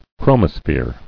[chro·mo·sphere]